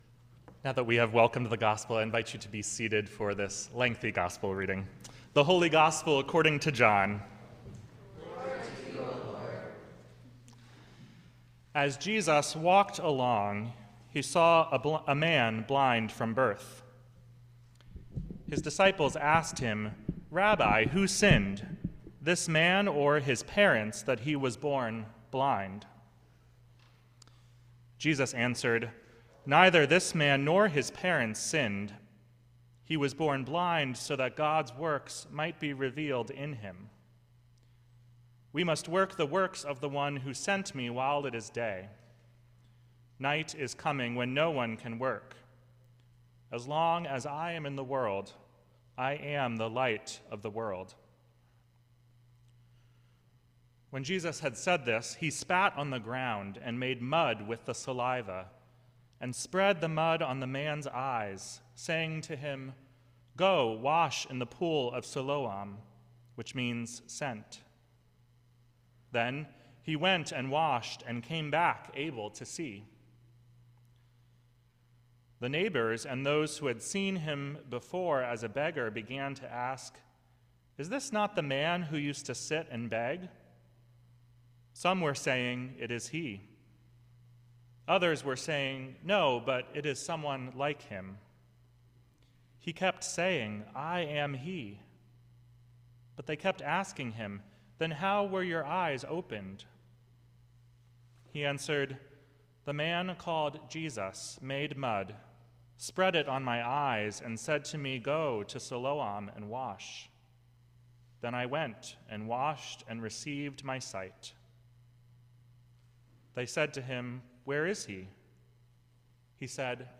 Sermon for the Fourth Sunday in Lent 2026